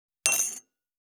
241,食器にスプーンを置く,ガラスがこすれあう擦れ合う音,コップ,工具,小物,雑貨,コトン,トン,ゴト,ポン,ガシャン,ドスン,ストン,カチ,タン,バタン,スッ,
コップ効果音厨房/台所/レストラン/kitchen物を置く食器